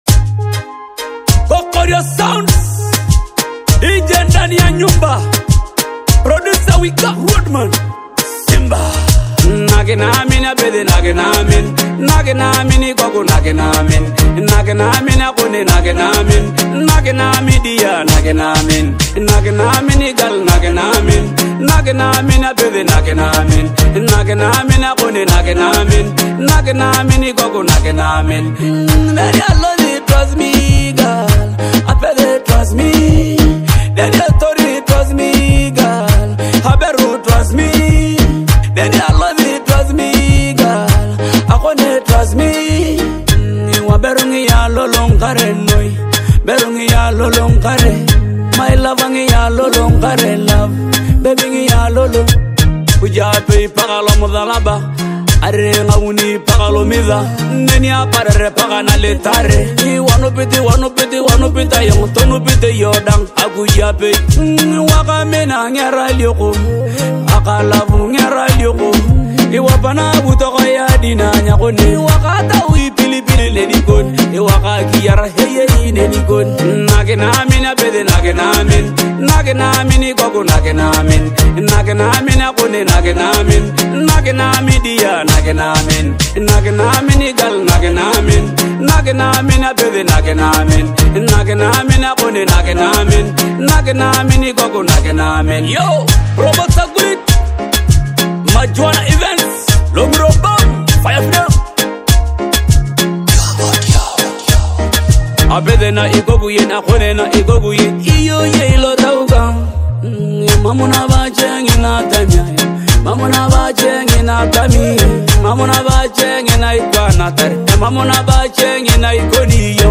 With touching lyrics and a smooth, melodic delivery